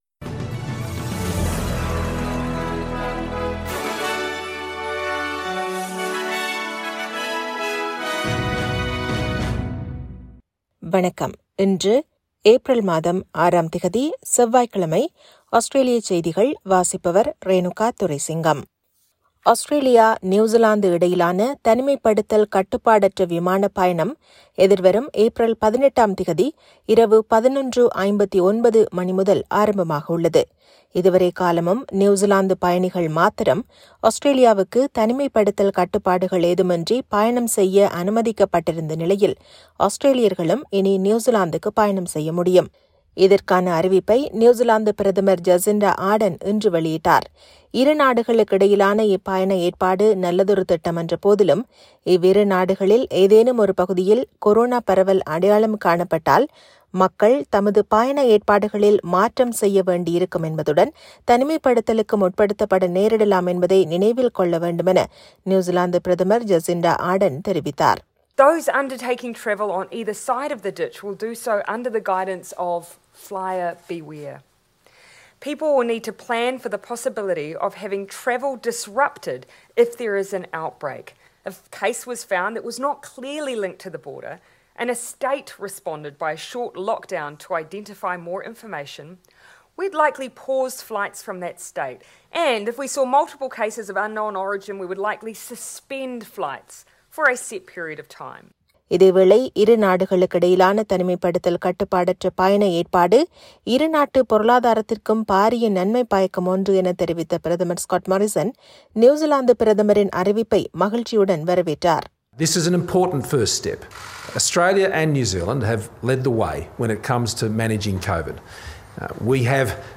SBS தமிழ் ஒலிபரப்பின் இன்றைய செவ்வாய்க்கிழமை (06/04/2021) ஆஸ்திரேலியா குறித்த செய்திகள்.